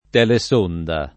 DOP: Dizionario di Ortografia e Pronunzia della lingua italiana
telesonda